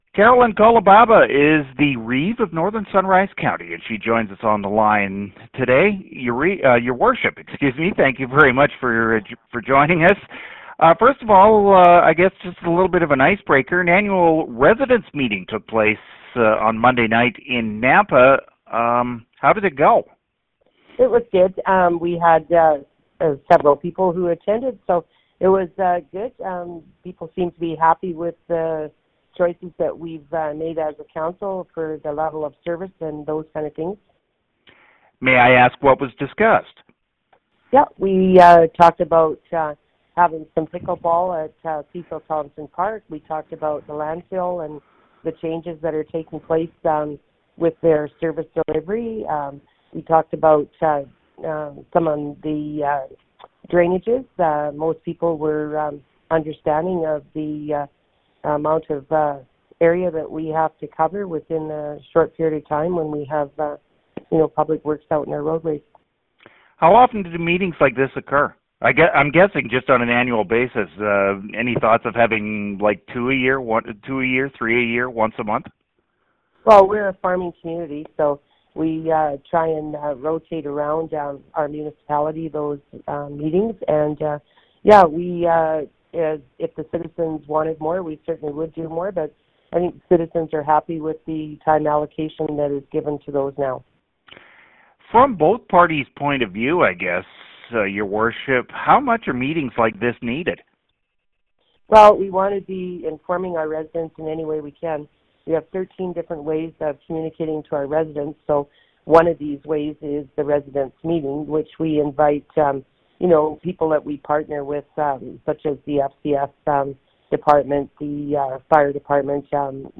Reeve Carolyn Kolebaba discusses topics brought up, services that Northern Sunrise County has, and what is on the horizon for the County.